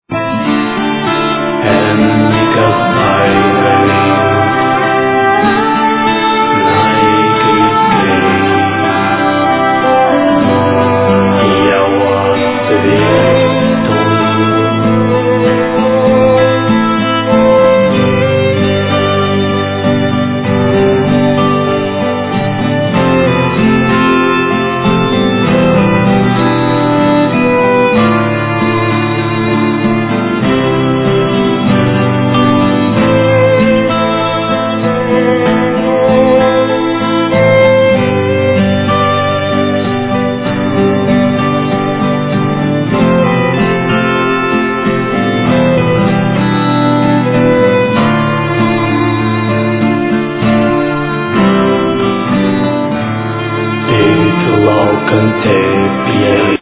Voices
Keyboards, Flute
Cello
Violin
Guitars
Voices, Sounds
Voice, Guitar